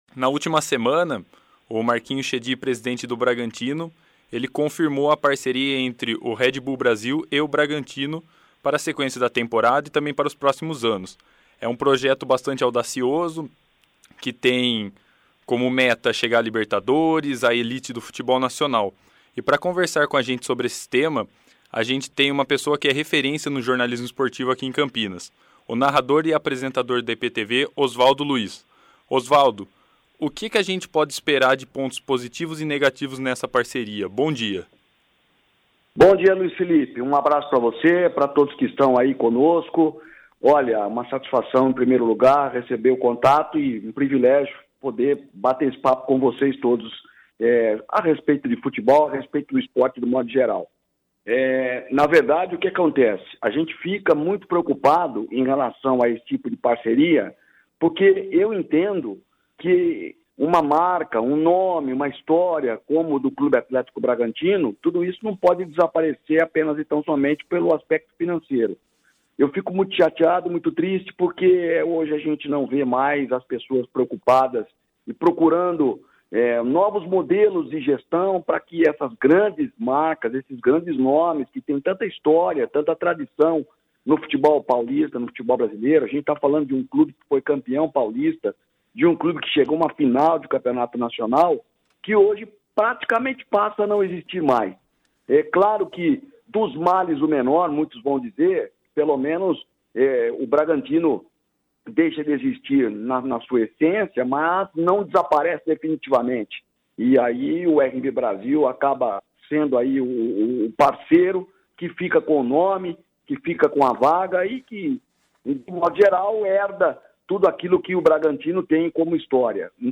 Entrevistas Notícias